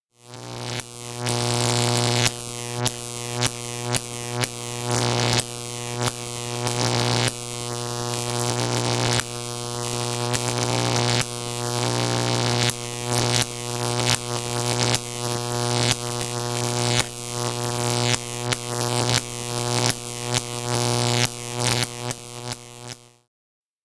electricity_high_voltage_spark_continuous_arcing